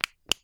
Flashlight